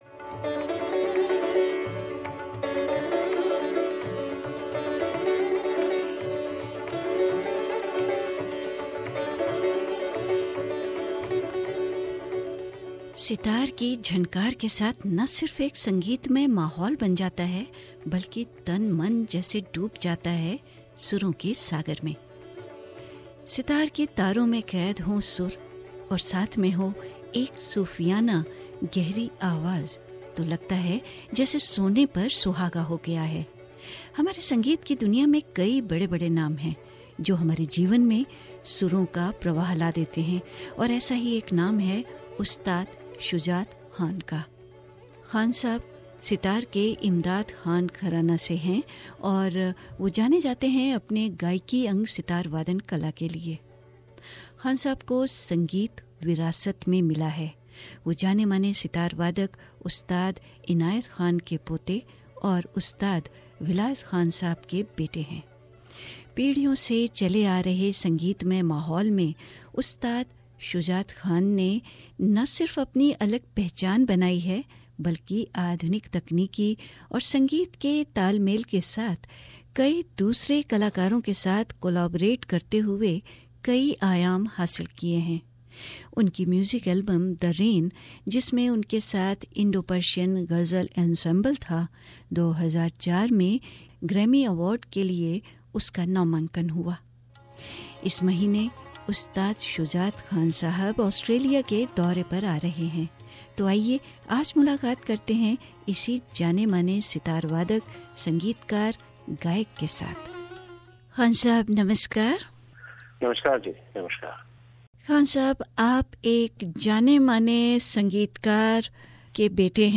Contrary to what he is often tagged as temperamental, Khan Sahib came across a very relax, content and in cheerful mood while speaking over the phone with SBS Hindi Radio prior to his tour to Australia.